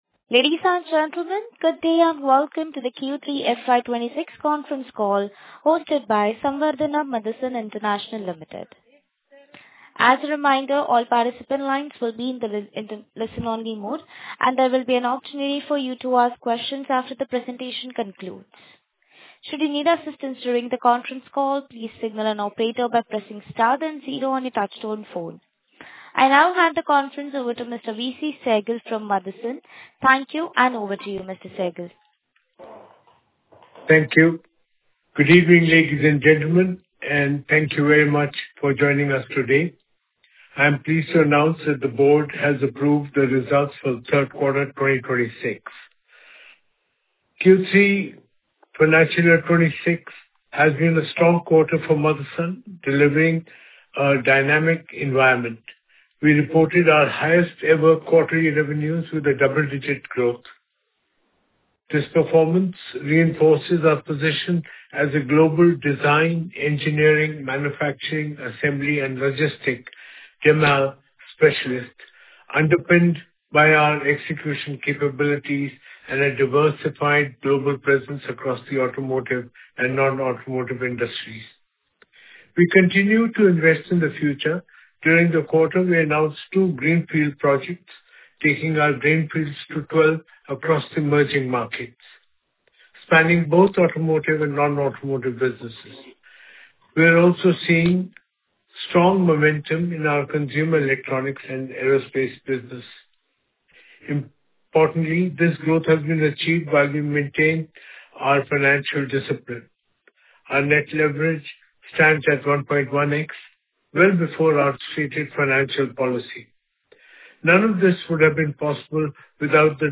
Concalls
Unedited-recording-of-conference-call-held-on-SAMIL-Q3FY26-results.mp3